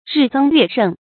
日增月盛 rì zēng yuè shèng
日增月盛发音
成语注音ㄖㄧˋ ㄗㄥ ㄩㄝˋ ㄕㄥˋ